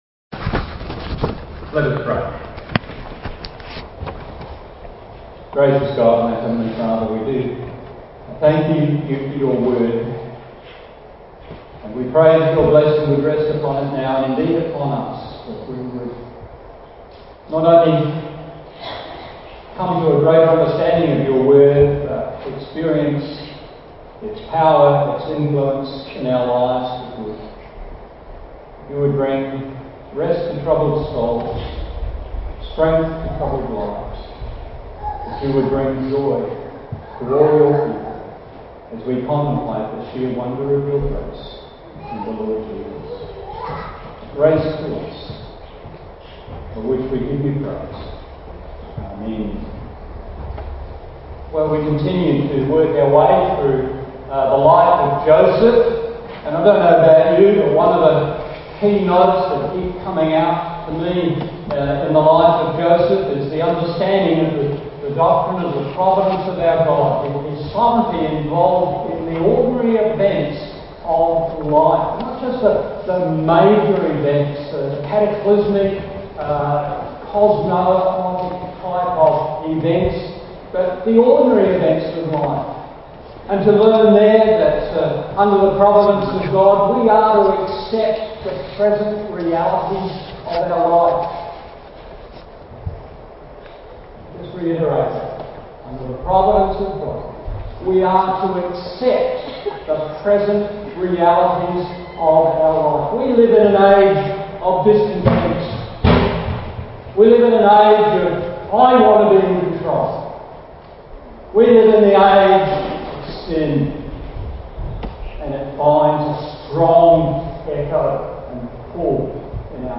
Guilt’s Acknowledgement Apologies for the sound quality of this recording